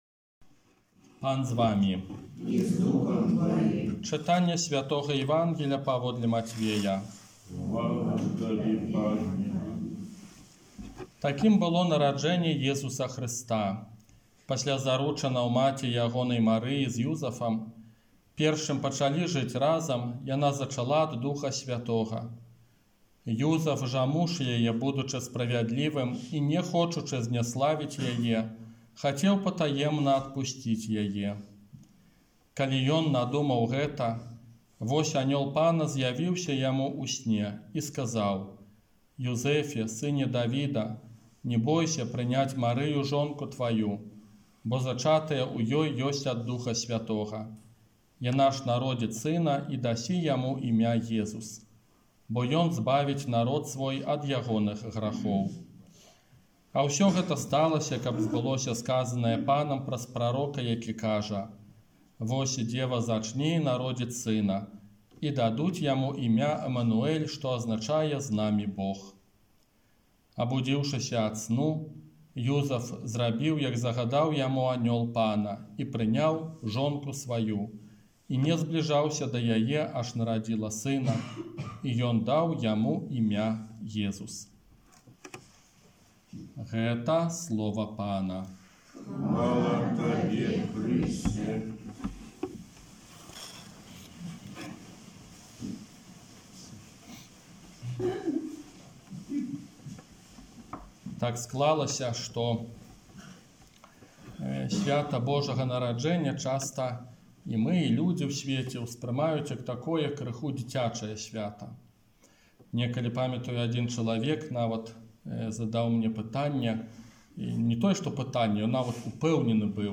ОРША - ПАРАФІЯ СВЯТОГА ЯЗЭПА
Казанне на Божае Нараджэнне 2022